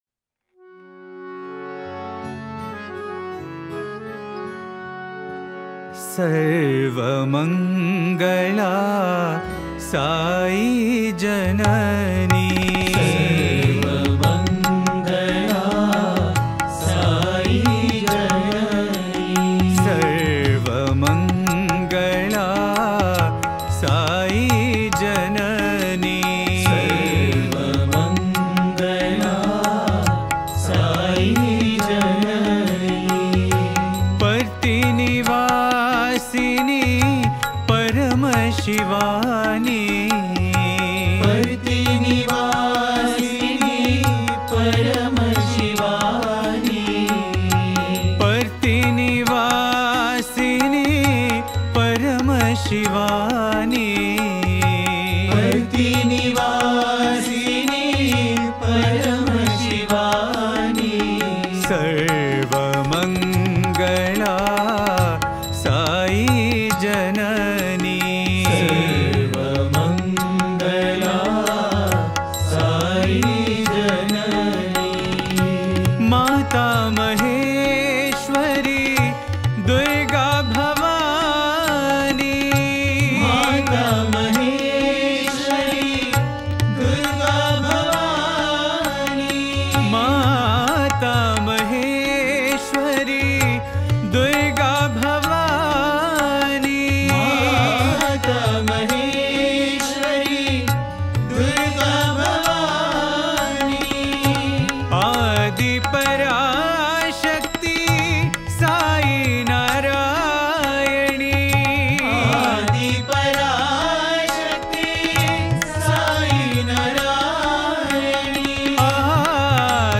Home | Bhajan | Bhajans on various Deities | Devi Bhajans | 52 – Sarva Mangala Sai Janani